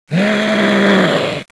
Index of /svencoop/sound/paranoia/zombie
zo_alert10.wav